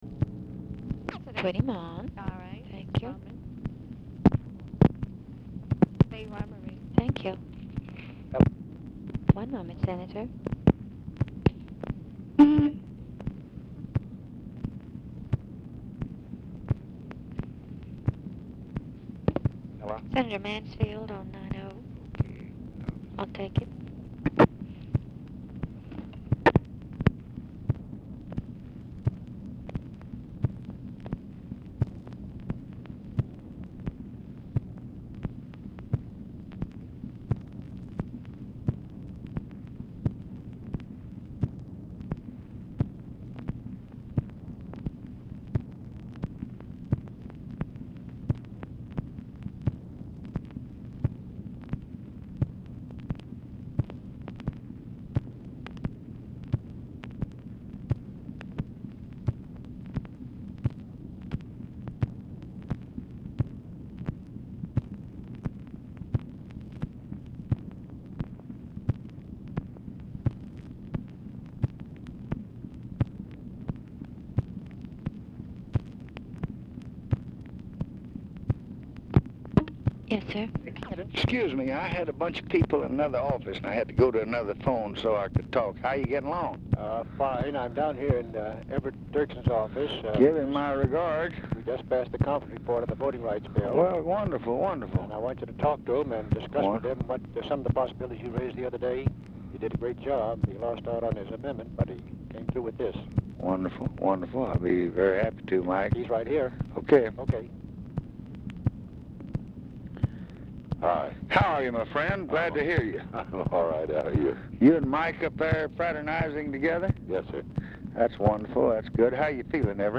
Oval Office or unknown location
"SUMMARIZED"; MANSFIELD ON HOLD 1:20; MANSFIELD IS MEETING WITH EVERETT DIRKSEN IN DIRKSEN'S OFFICE AT TIME OF CALL
Telephone conversation
Dictation belt